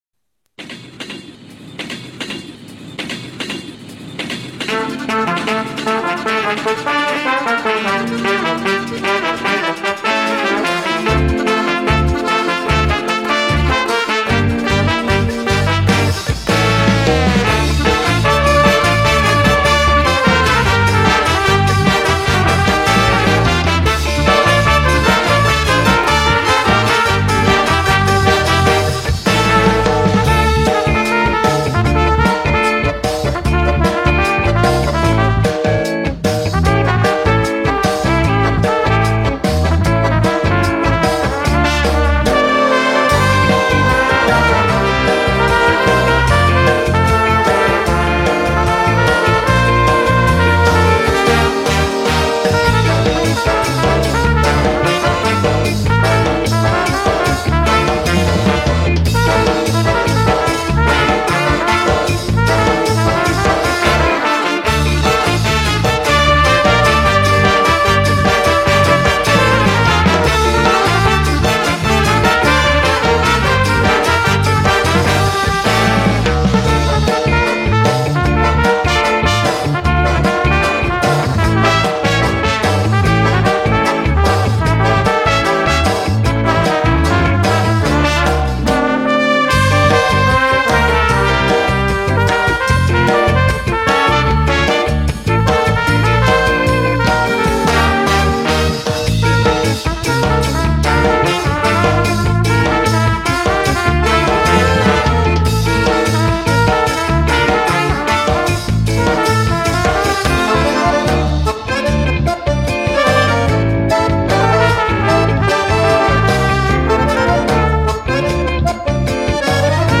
инструментальная версия (труба)